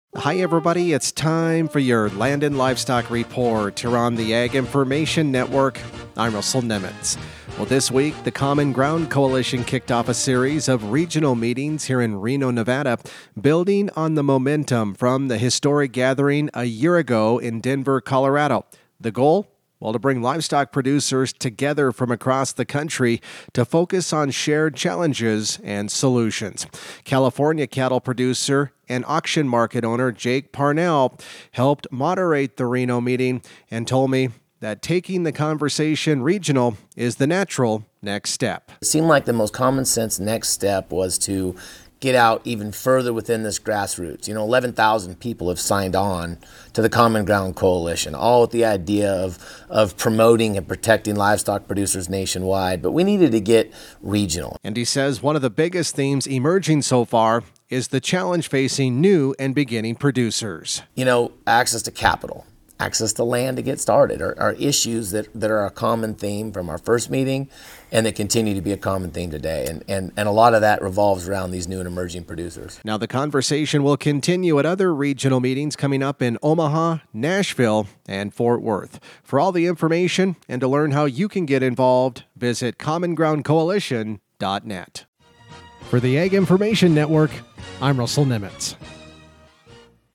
Wednesday Apr 22nd, 2026 57 Views Land & Livestock Report